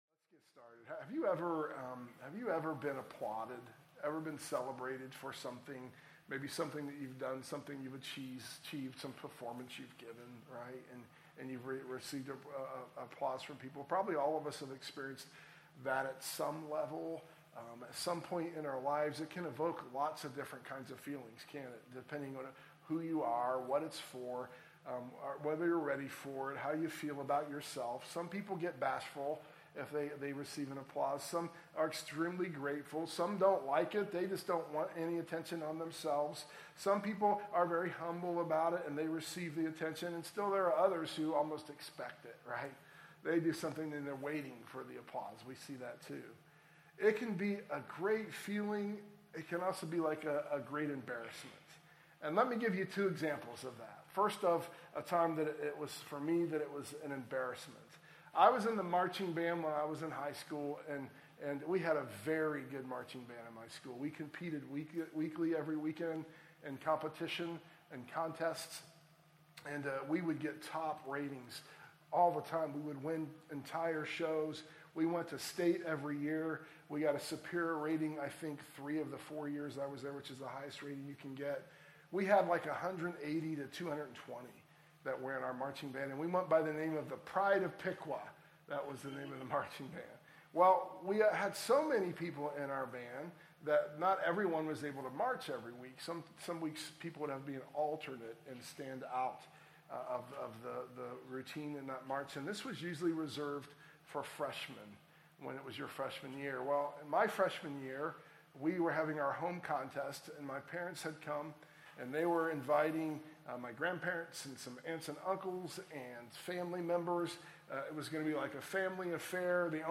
sermon_audio_mixdown_5_19_24.mp3